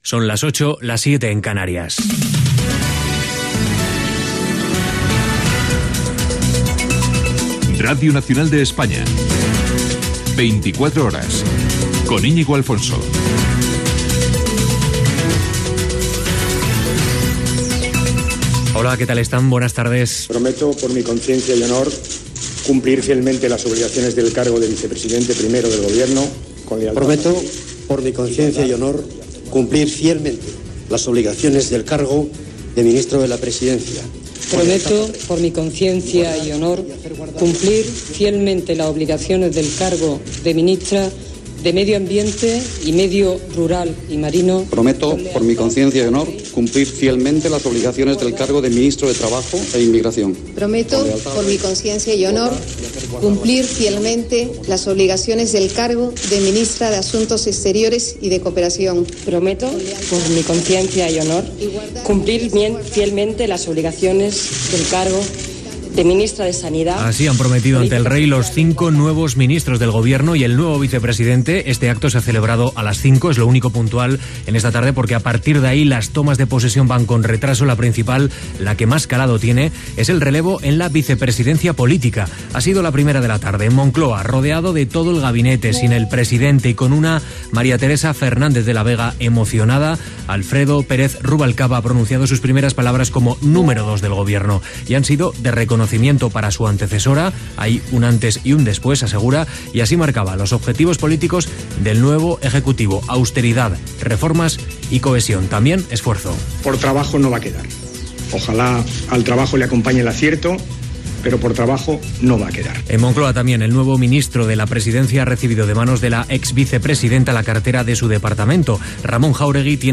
Careta del programa i jurament del càrrec dels nous ministres del Govern espanyol.
Informatiu
FM